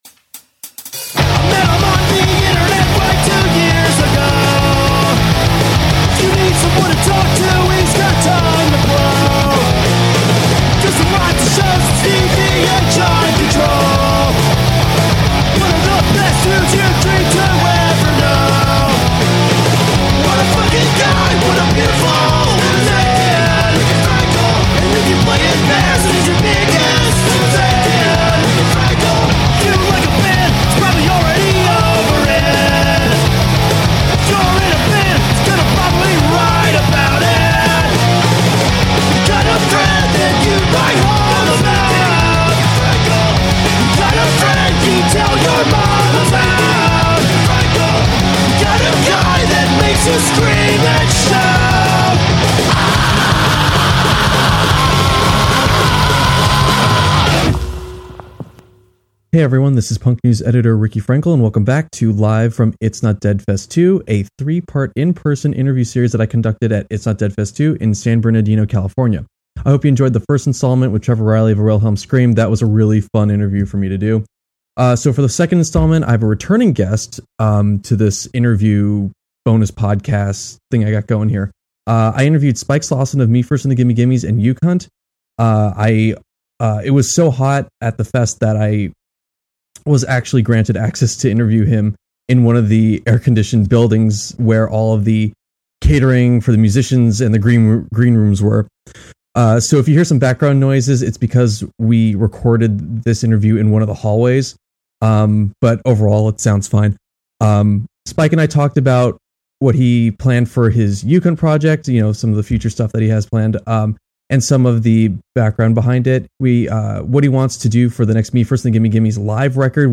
live at It's Not Dead Fest 2.